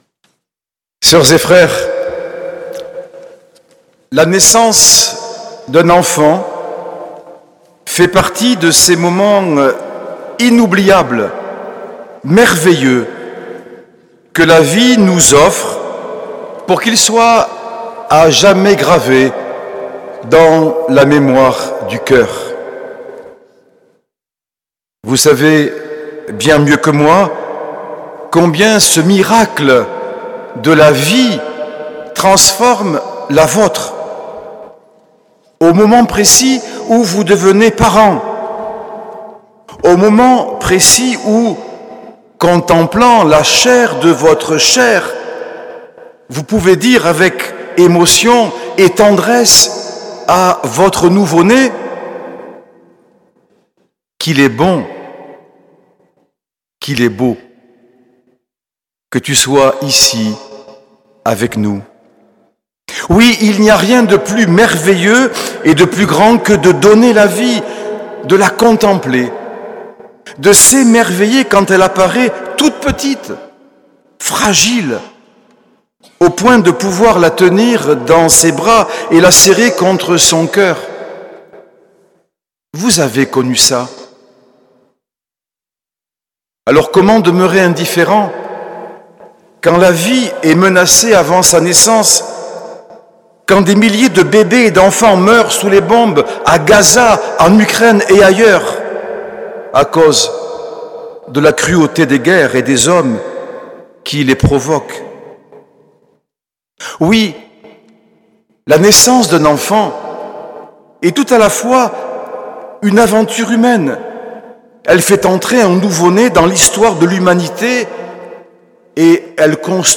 L’enregistrement retransmet l’homélie de Monseigneur Norbert TURINI.